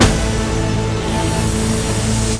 68_22_stabhit-A.wav